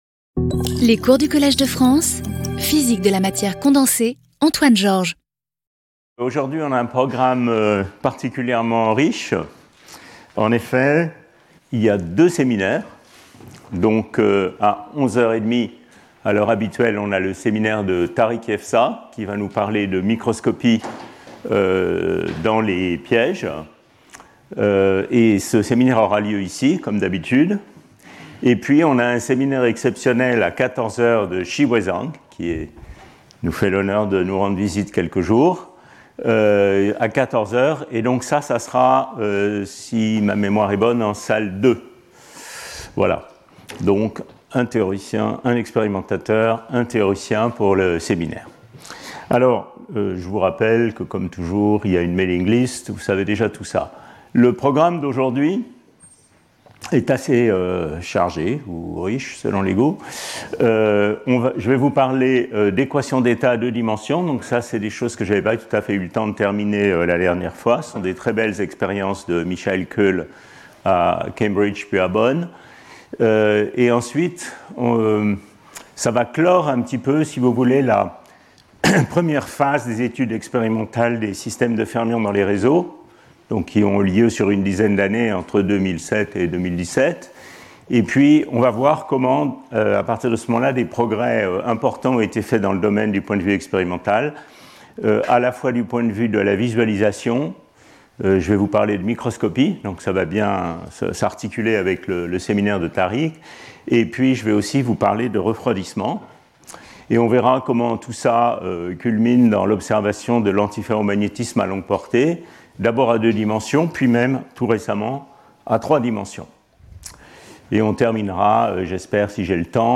Antoine Georges Professor at the Collège de France
Lecture